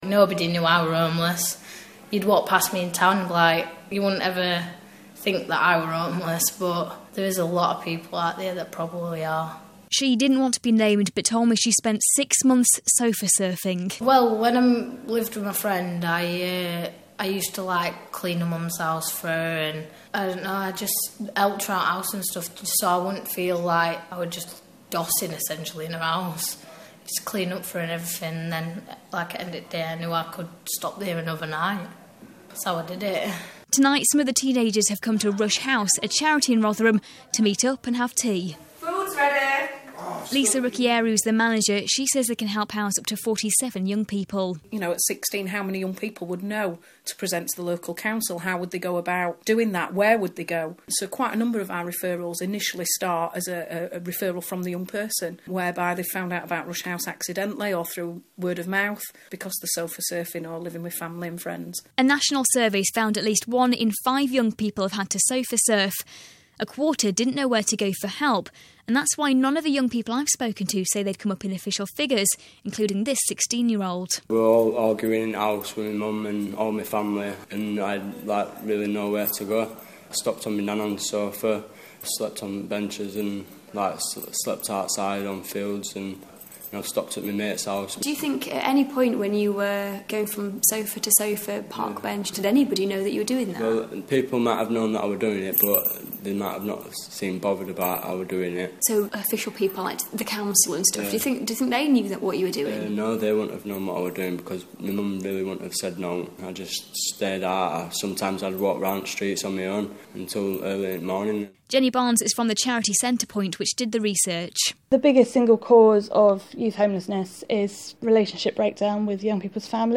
Facebook Twitter Headliner Embed Embed Code See more options Teenagers who've been homeless in South Yorkshire tell us how they've spent nights on park benches and relied on friends putting them up.